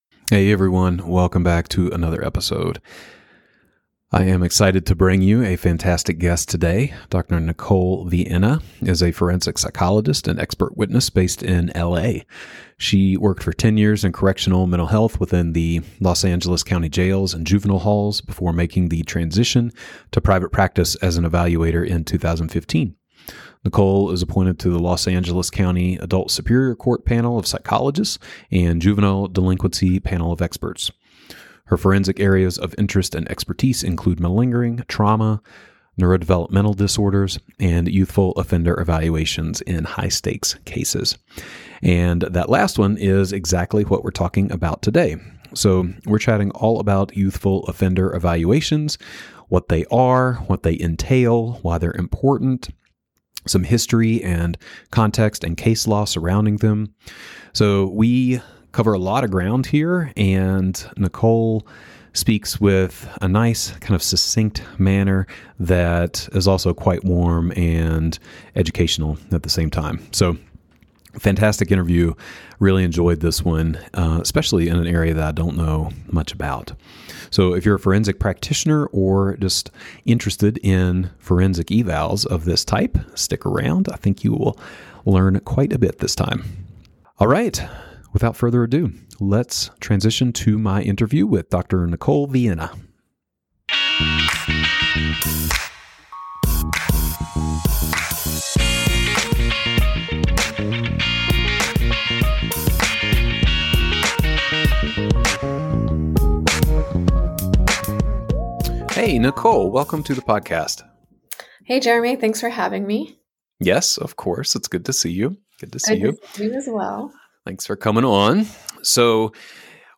Recorded audio with transcript. Want to hear the interview?